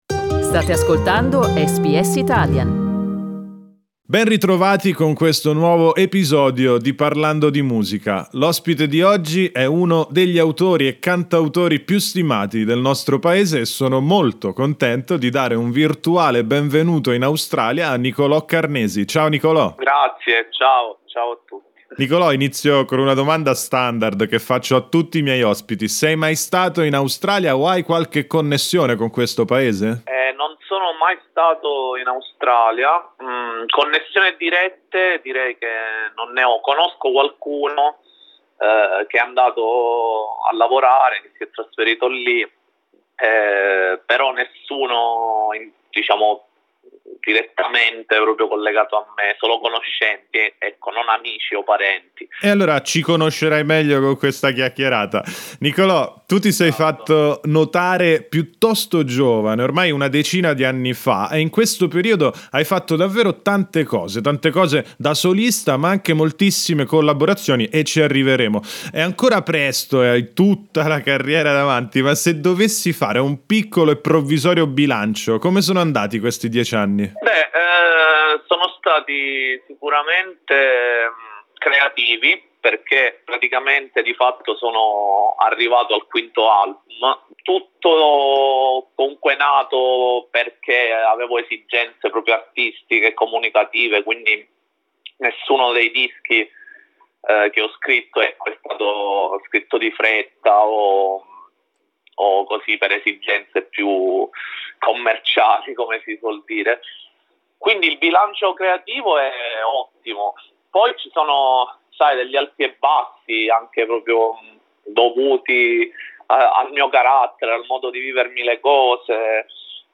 Il cantautore siciliano racconta i retroscena del suo nuovo singolo, uscito il 30 aprile scorso, e dei suoi primi 10 anni di carriera.